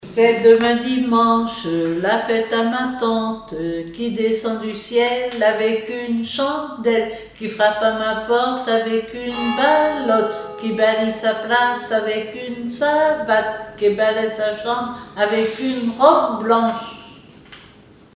Rondes enfantines à baisers ou mariages
formulette enfantine : amusette
Pièce musicale inédite